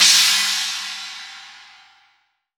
8SA CYMB.wav